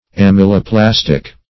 Search Result for " amyloplastic" : The Collaborative International Dictionary of English v.0.48: Amyloplastic \Am`y*lo*plas"tic\, a. [Amylum + -plastic.] Starch-forming; amylogenic.
amyloplastic.mp3